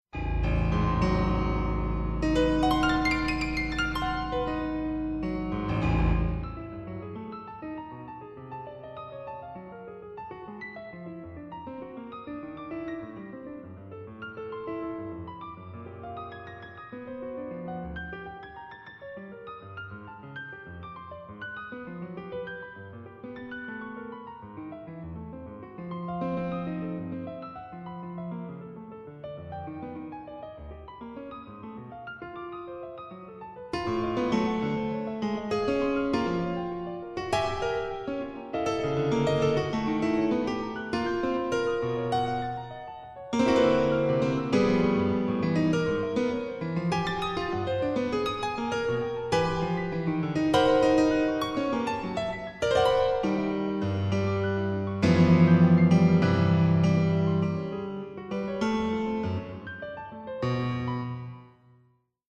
Australian classical music
Australian pianist and composer
Classical, Keyboard